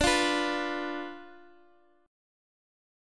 Dm6 chord